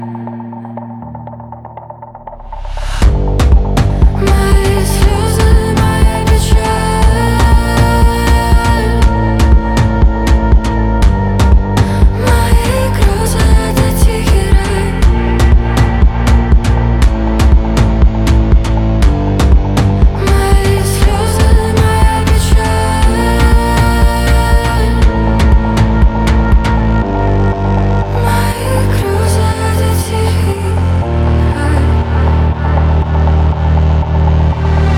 Pop Singer Songwriter